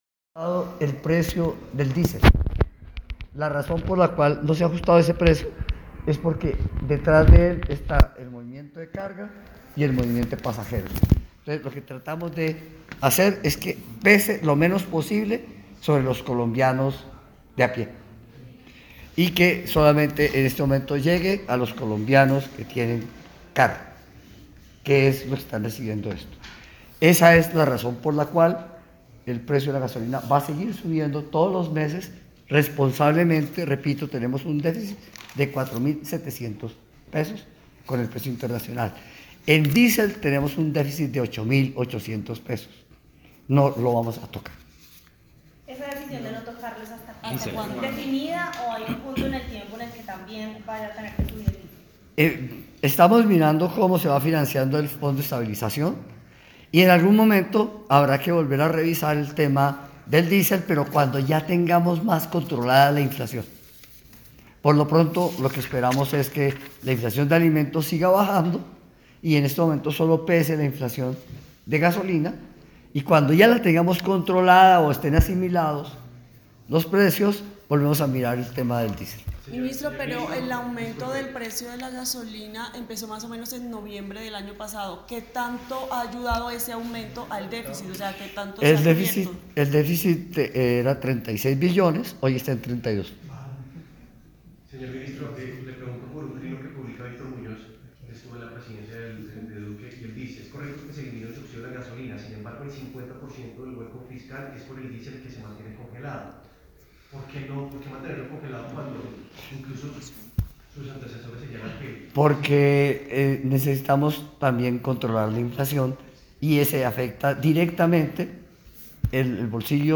Declaraciones del Ministro, Ricardo Bonilla Tema - Gasolina